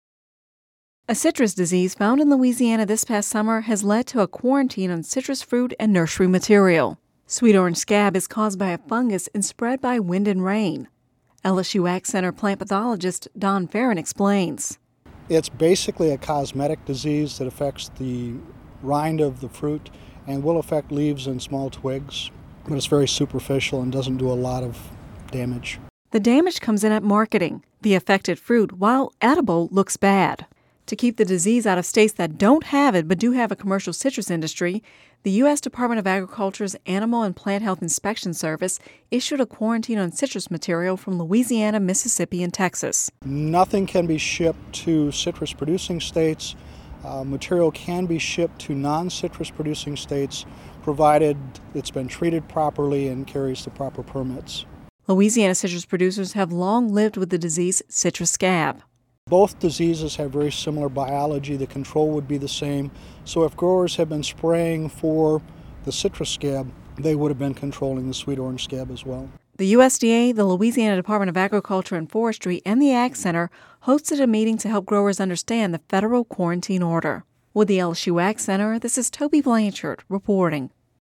(Radio News 01/17/11) A citrus disease, found in Louisiana this past summer, has led to a quarantine on citrus fruit and nursery material. Sweet orange scab is caused by a fungus and spread by rain and wind.